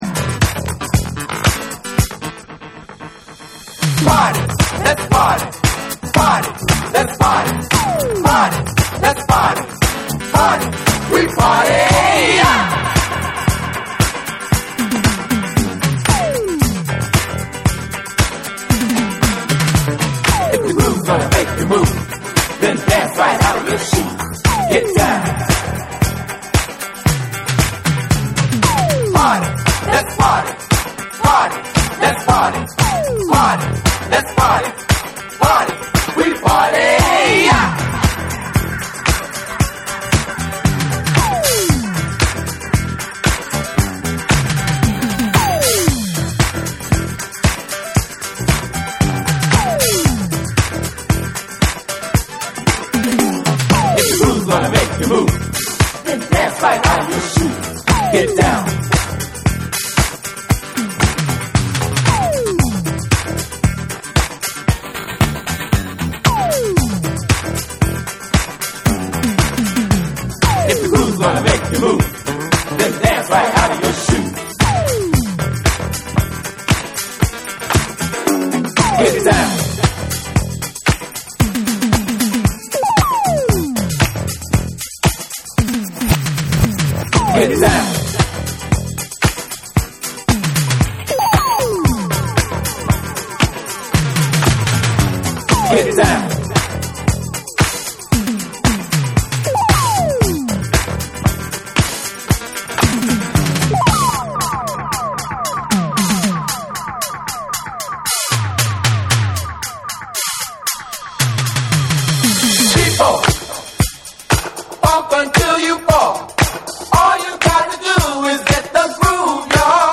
サイケ感も打ち出しながらエレクトリックかつファンキー・グルーヴが炸裂するディスコ・エディット
自然と身体に入り込む唸りまくるベースラインがリードするトラックに、男性ヴォーカルが気持ち良く絡み展開する
DANCE CLASSICS / DISCO / RE-EDIT / MASH UP